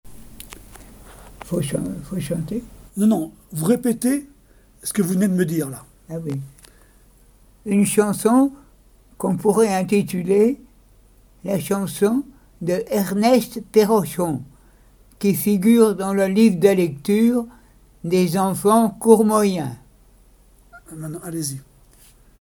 Jard-sur-Mer
chansons traditionnelles et d'école
Catégorie Témoignage